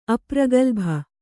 ♪ apragalbha